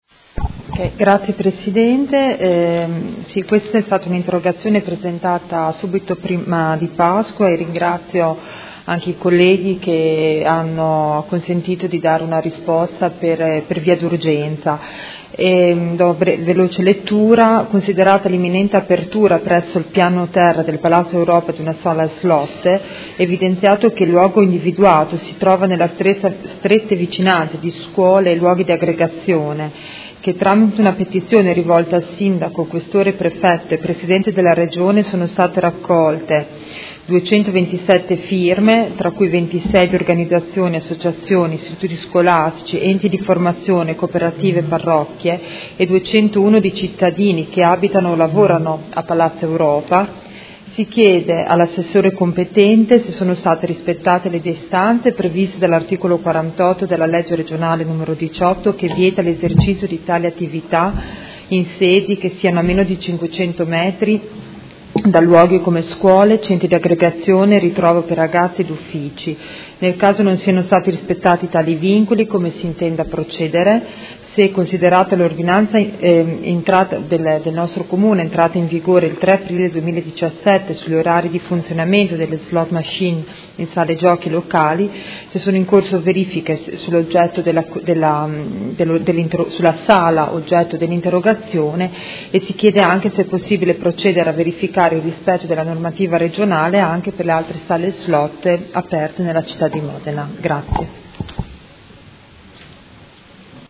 Seduta del 27/04/2017 Interrogazione dei Consiglieri Baracchi e Poggi (PD) avente per oggetto: Apertura Sala Slot c/o piano terra Palazzo Europa.